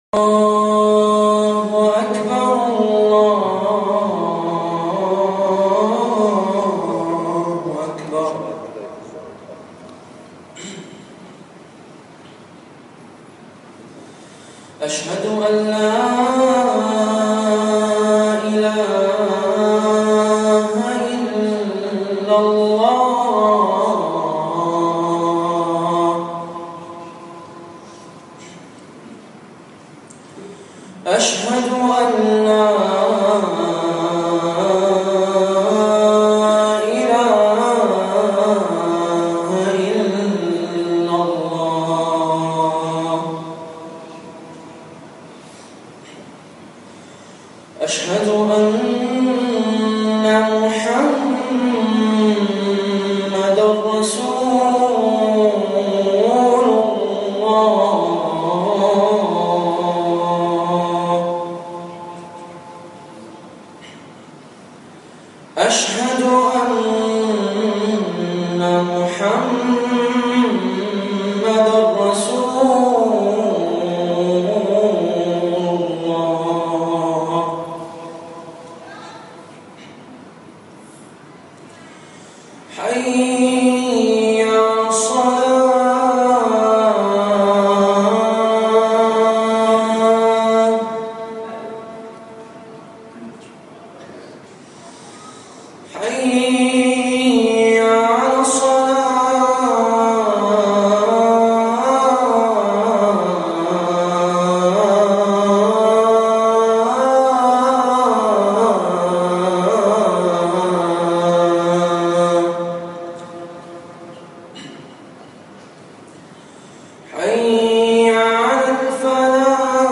الخلاف- 2 شوال - خطب جمعة